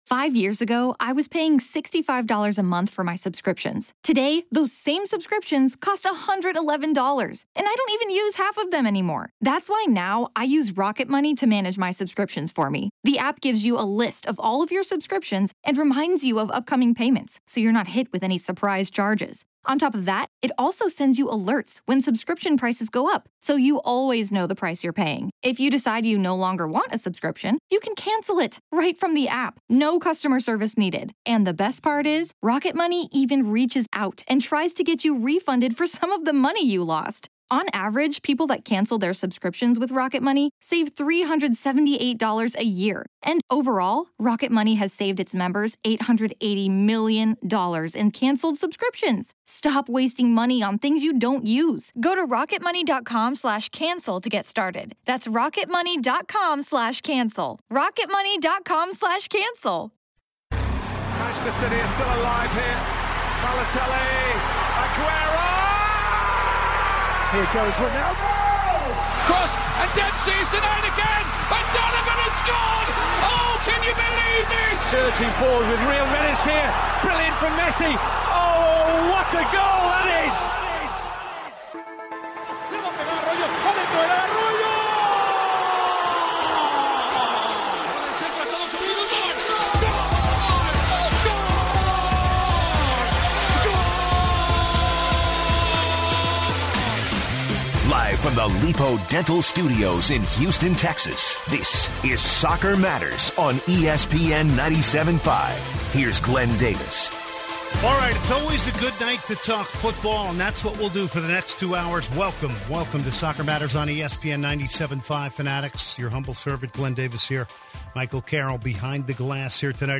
Also this hour, callers weigh in on Barcelona's 7th time semi-finals advancement and share thoughts on the US vs. Mexico game. Fox Analyst, Warren Barton calls in.